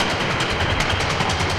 RI_DelayStack_150-02.wav